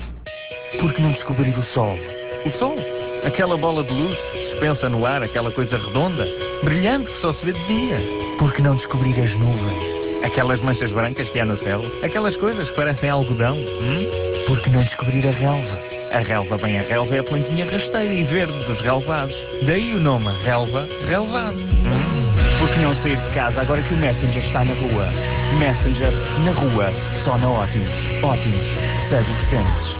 Em simultâneo com outdoor, também dia 25 de Maio estreou a campanha de rádio da Optimus a anunciar um novo serviço - o Messenger no telemóvel.